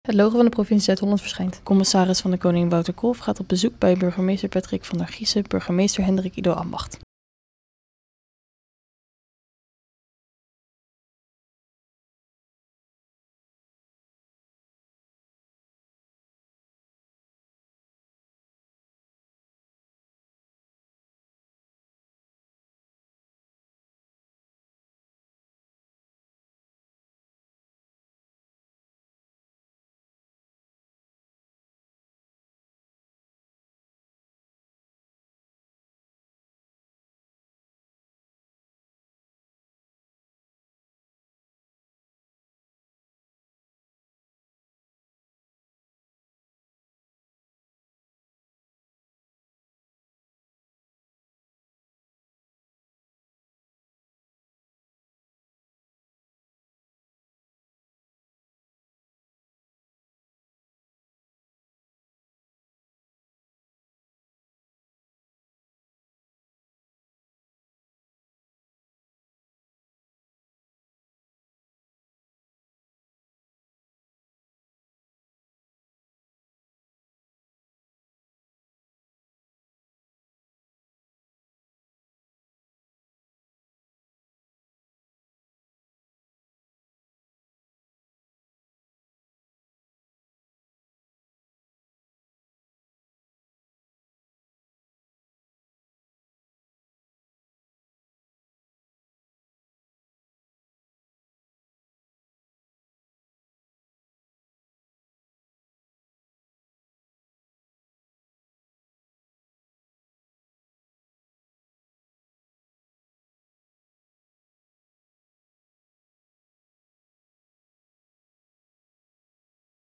CdK in gesprek met burgemeester Hendrik-Ido-Ambacht